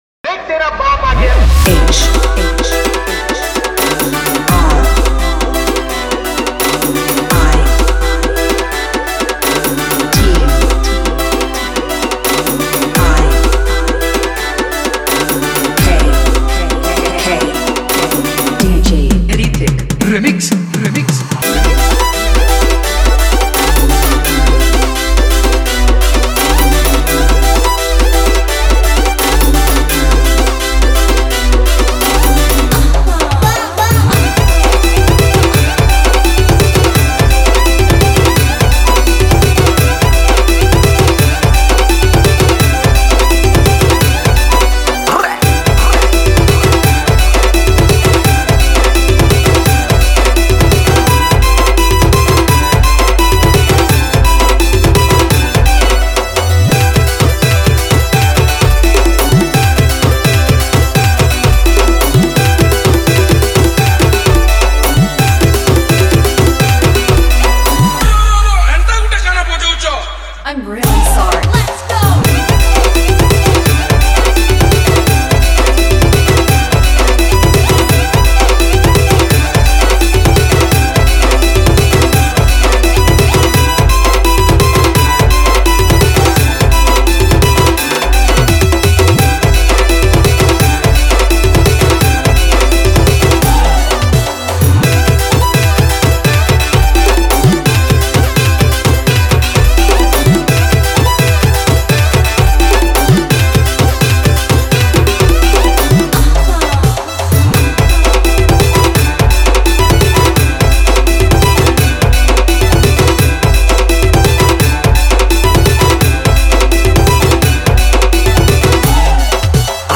Hulahuli & Nagin Music Collection